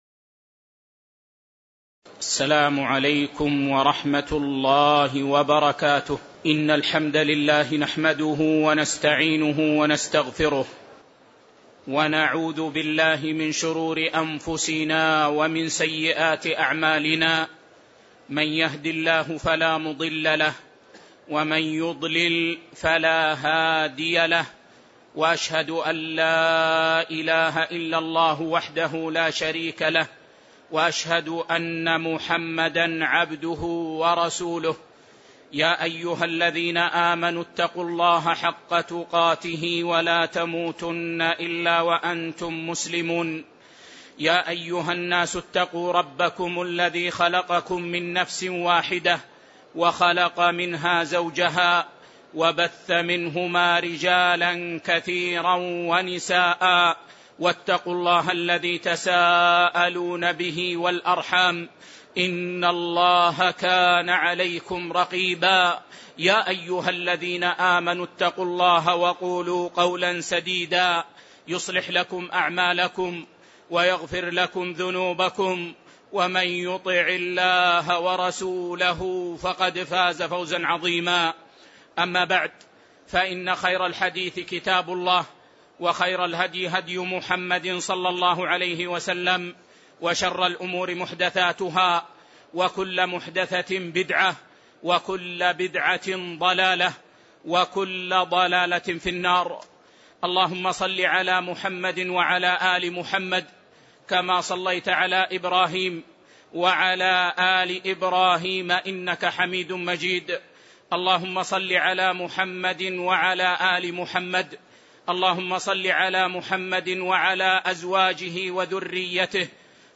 تاريخ النشر ٢٦ ربيع الثاني ١٤٣٧ هـ المكان: المسجد النبوي الشيخ